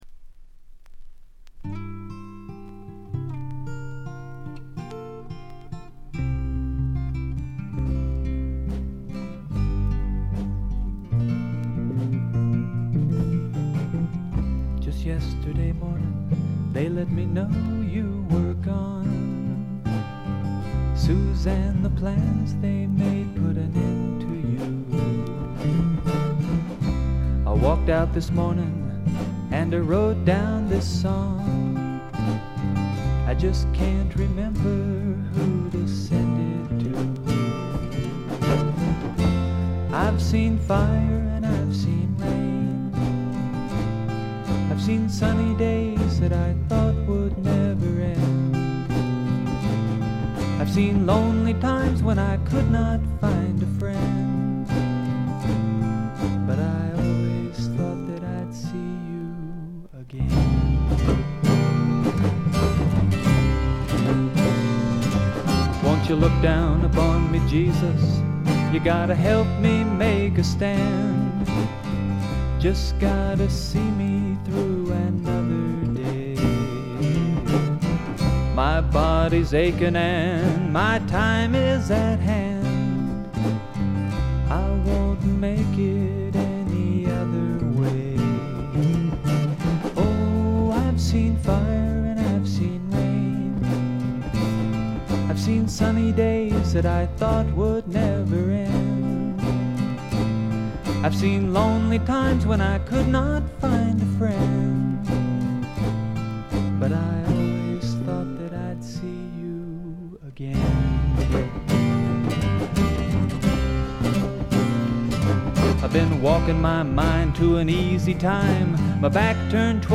ごくわずかなノイズ感のみ。
マイナーなフォーキー・シンガーソングライター
試聴曲は現品からの取り込み音源です。
Guitar, Vocals Liner Notes